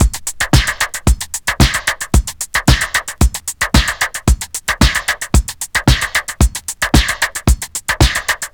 Tuned drums (D# key) Free sound effects and audio clips
• 112 Bpm Drum Beat D# Key.wav
Free drum beat - kick tuned to the D# note. Loudest frequency: 2289Hz
112-bpm-drum-beat-d-sharp-key-3TR.wav